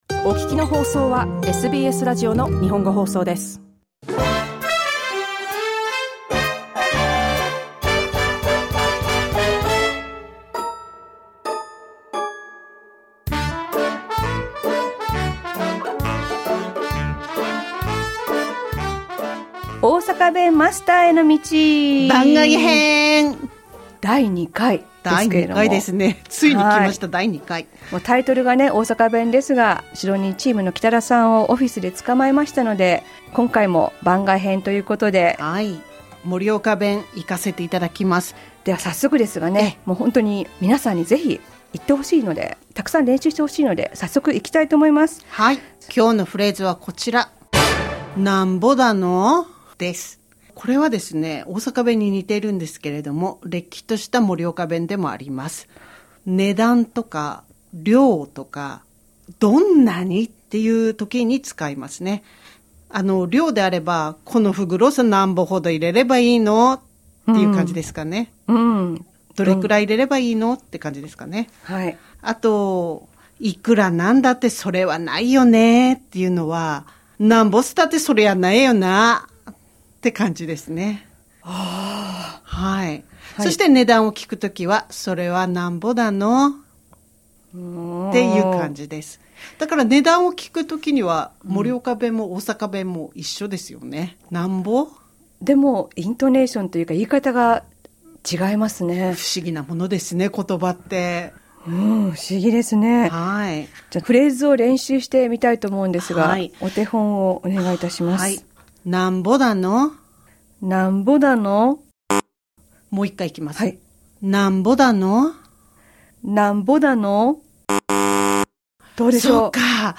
The Japanese language is truly rich in diversity. Let's learn the Morioka dialect with SBS Japanese Sydney team.